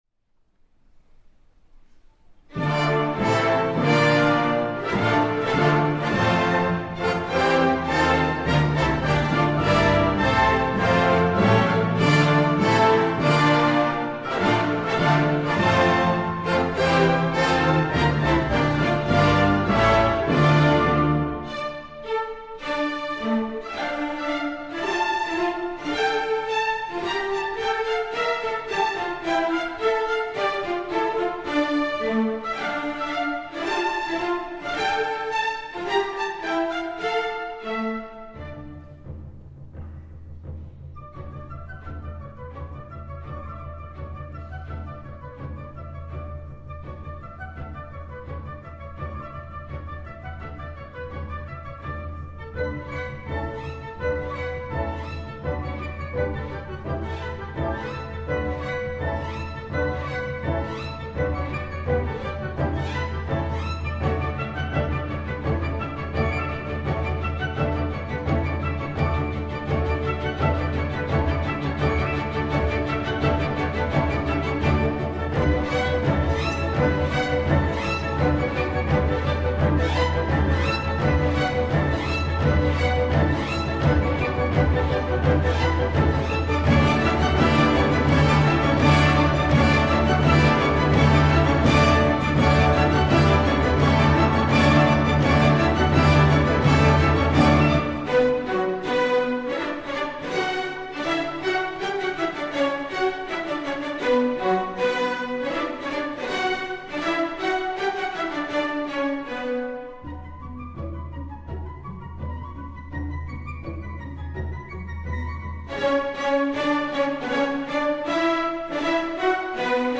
管弦楽